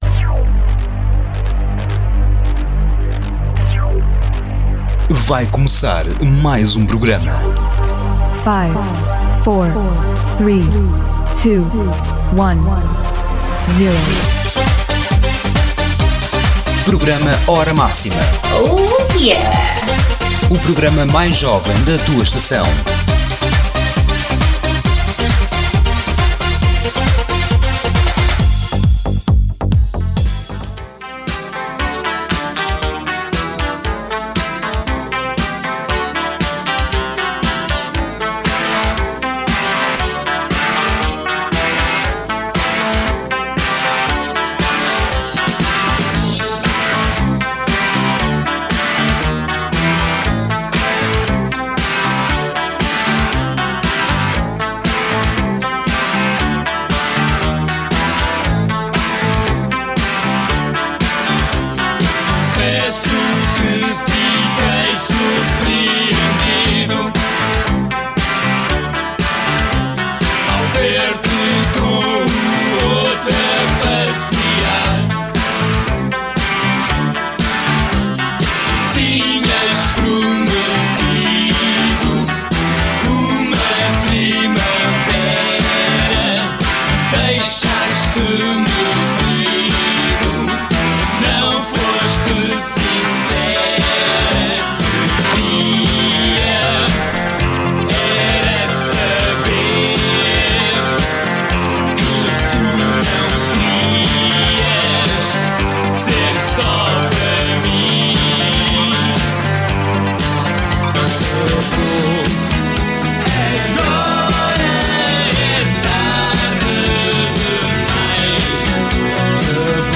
On this show I’m going to tell a bit and play some songs of this amazing and iconic portuguese bands.